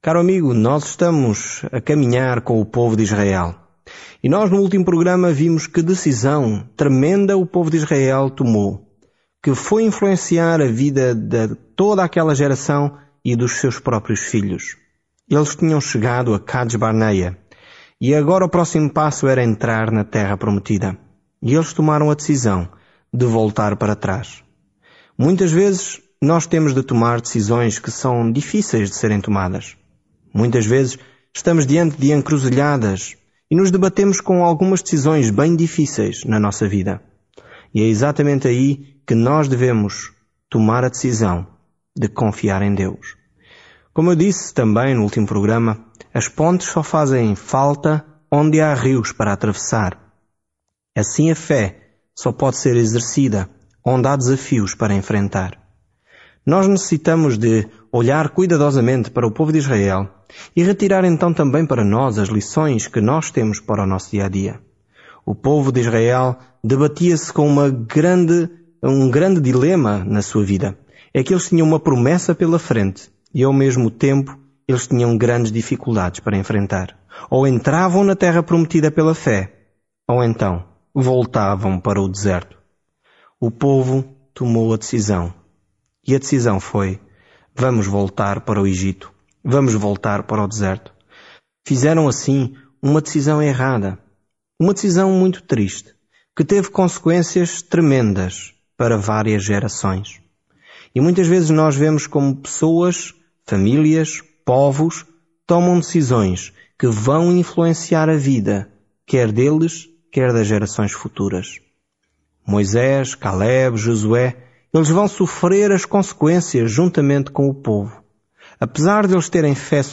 Escritura NÚMEROS 15 Dia 9 Iniciar este Plano Dia 11 Sobre este plano No livro de Números, estamos caminhando, vagando e adorando com Israel durante os 40 anos no deserto. Viaje diariamente por Números enquanto ouve o estudo em áudio e lê versículos selecionados da palavra de Deus.